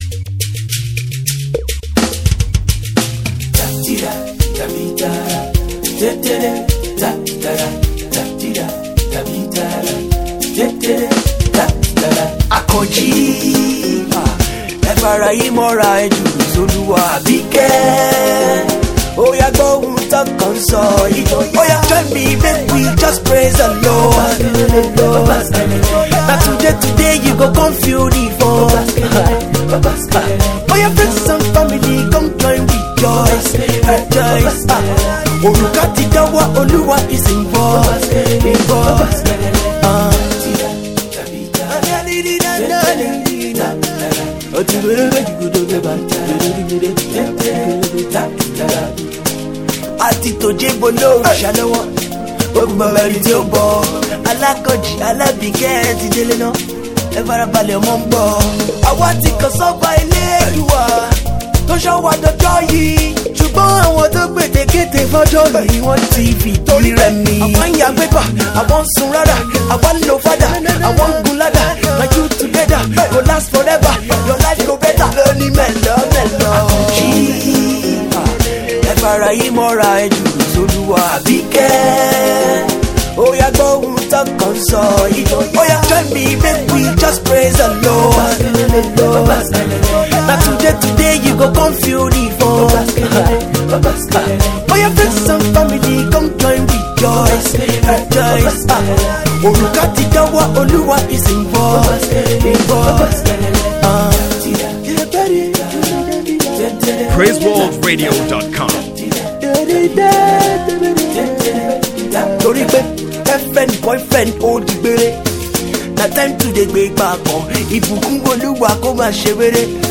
self produced fresh love single
afro/pop/rap